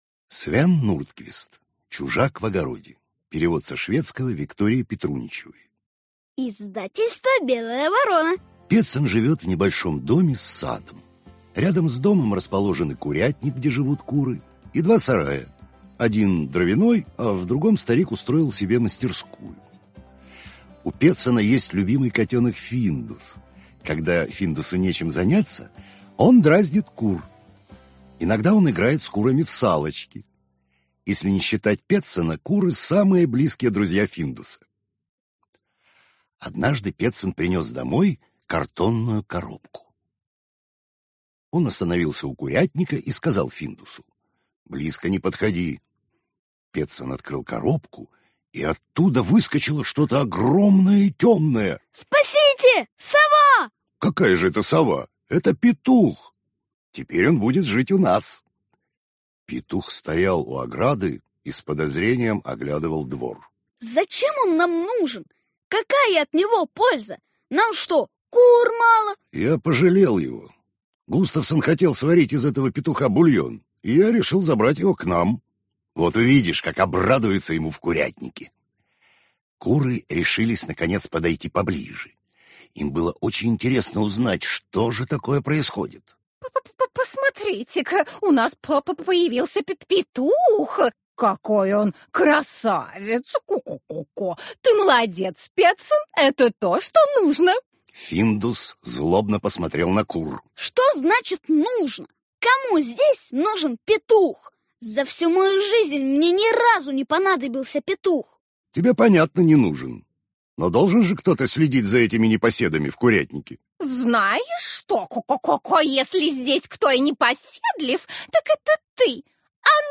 Аудиокнига Чужак в огороде | Библиотека аудиокниг